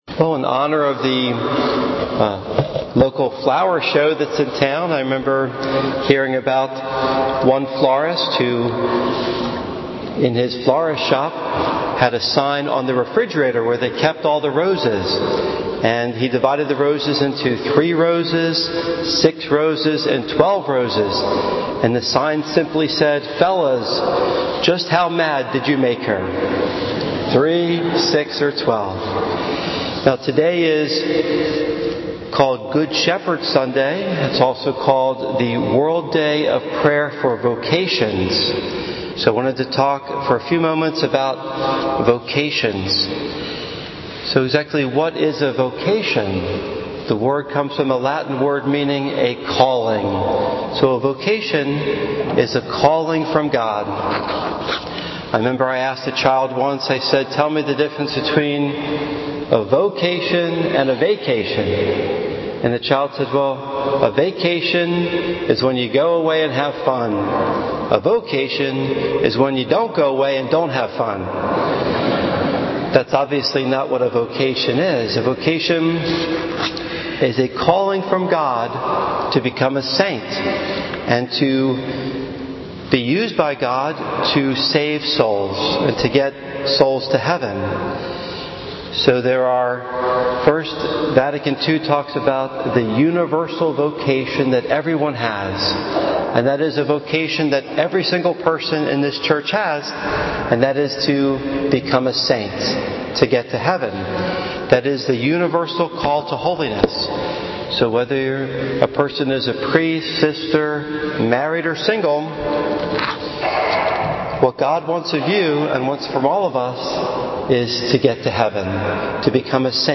Audios - Sunday Homilies